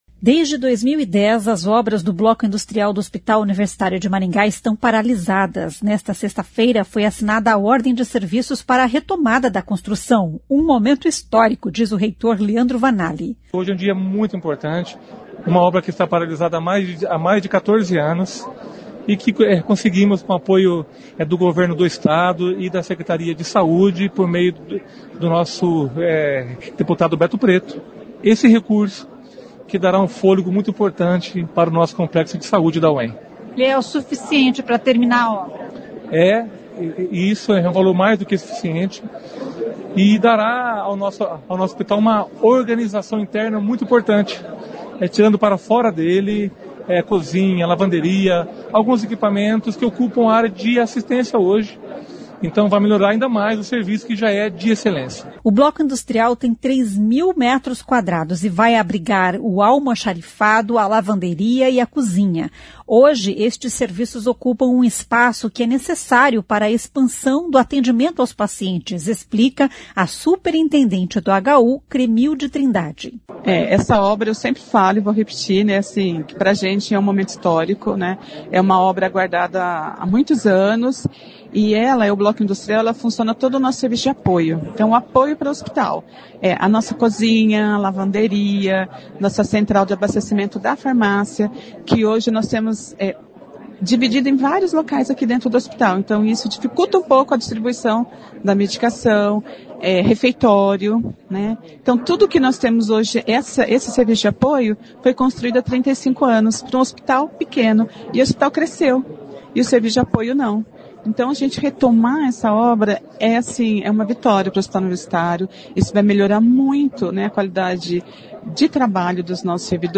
Em entrevista à CBN ele falou sobre este e outros assuntos.